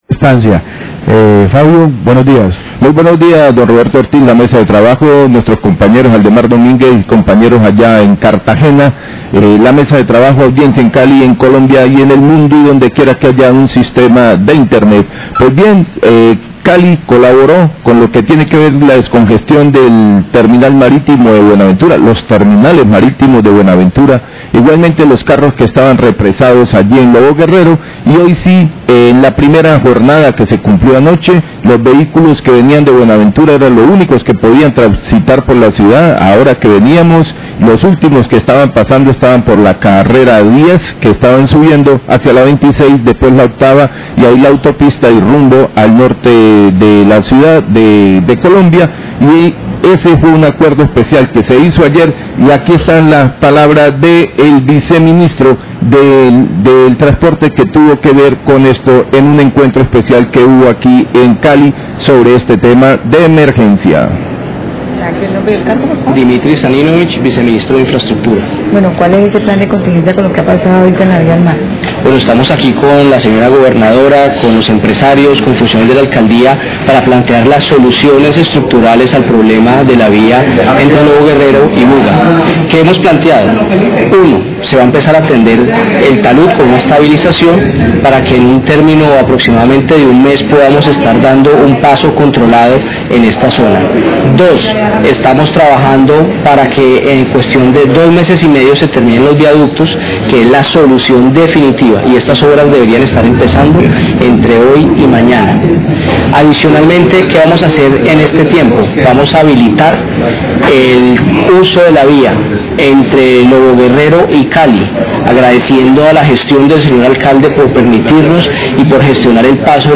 Radio
Sobre el acuerdo donde se estableció el plan de contigencia para la solución en la movilidad y la infraestructura en la vía de loboguerrero, se refirió el viceministro de transporte, la gobernadora y el Alcalde de Cali.